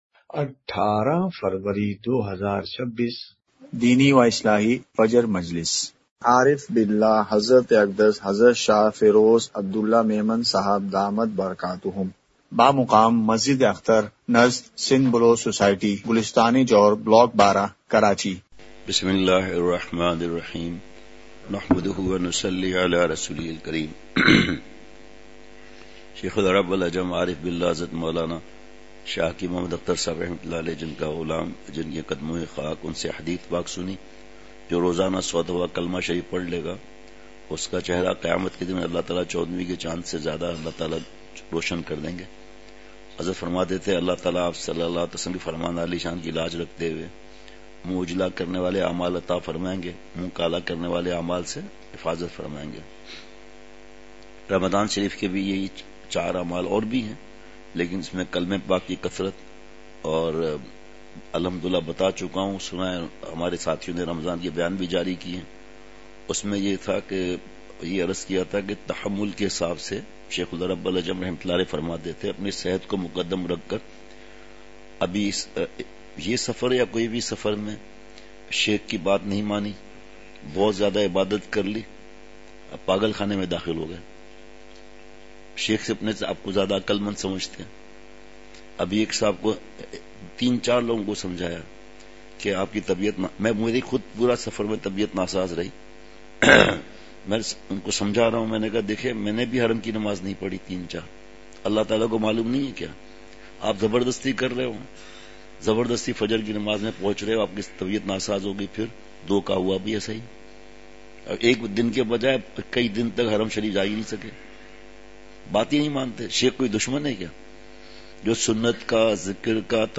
اصلاحی مجلس کی جھلکیاں *مقام:مسجد اختر نزد سندھ بلوچ سوسائٹی گلستانِ جوہر کراچی*